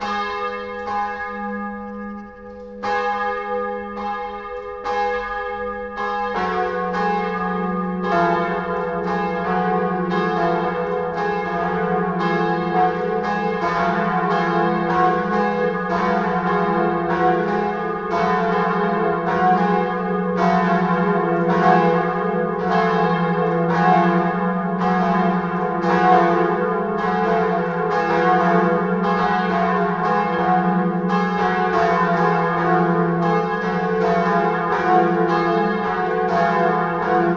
BELLS     -R.wav